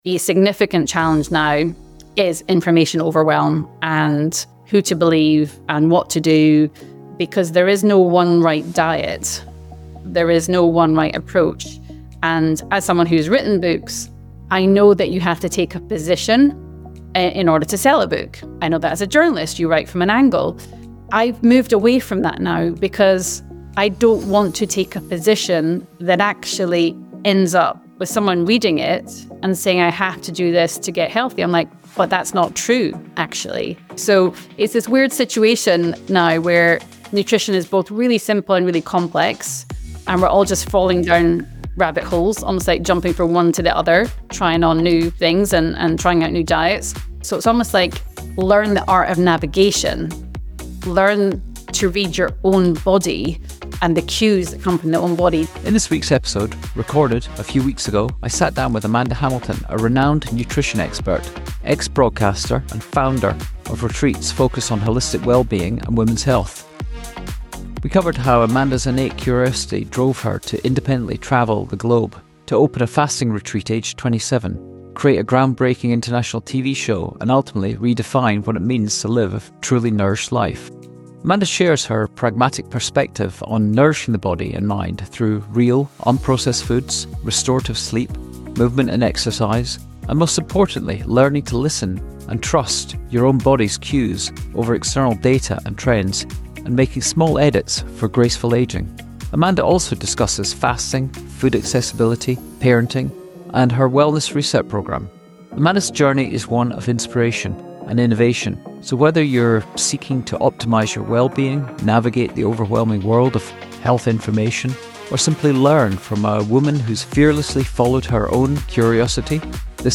interview with the renowned nutrition expert